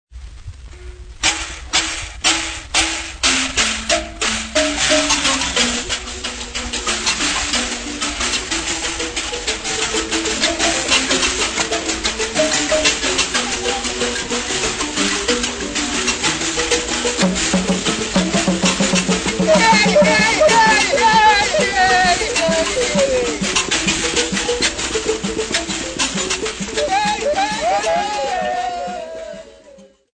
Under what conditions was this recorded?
Field recordings Africa, Sub-Saharan